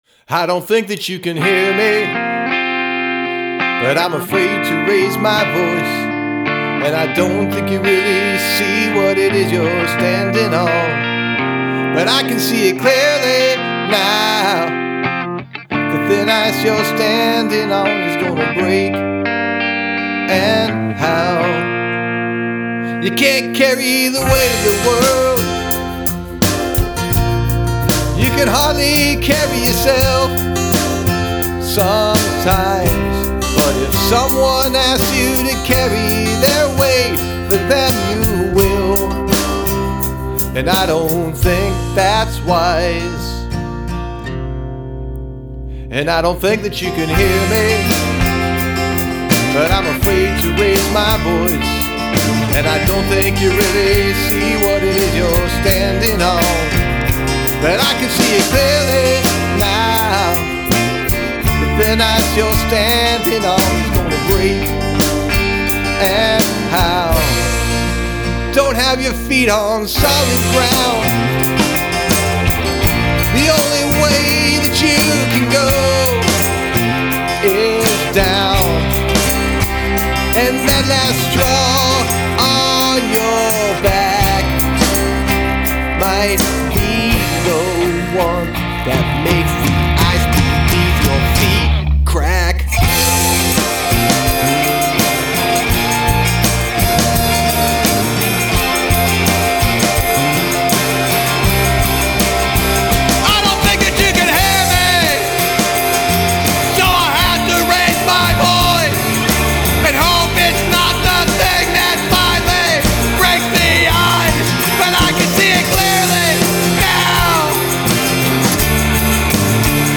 Significant Use of Shouting